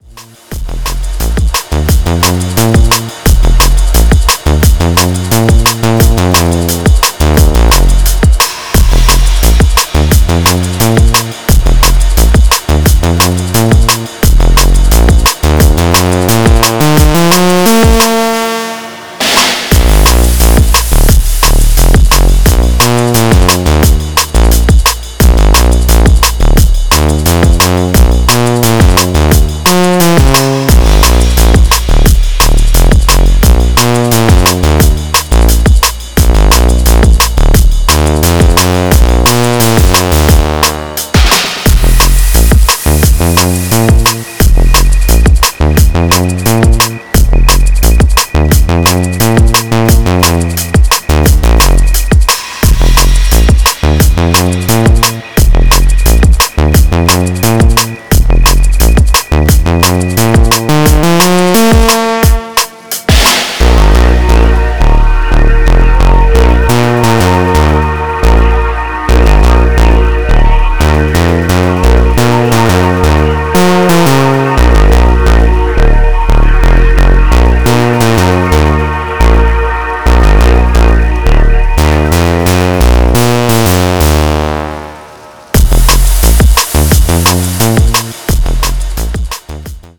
Styl: Drum'n'bass